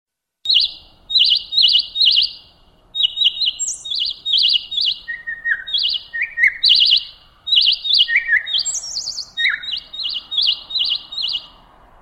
Naturljud, Fågelsång, Djur